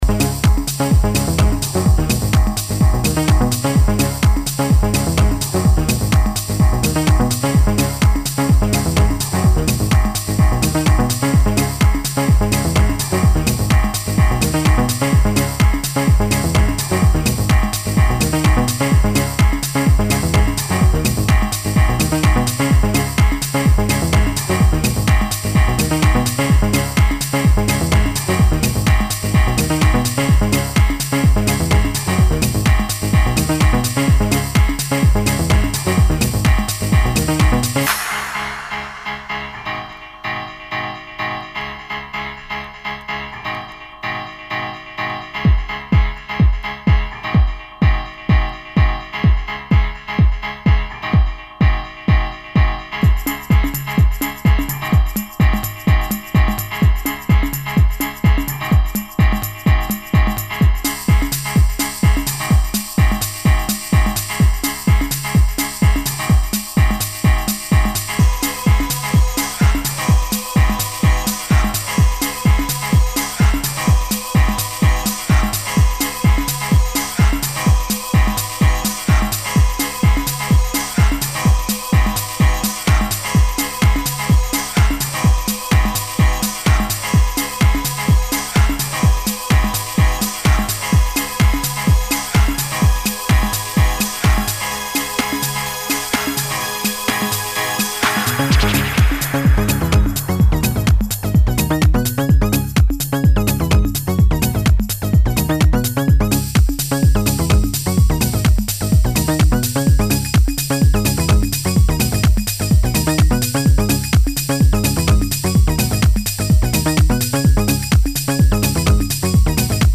House-Trance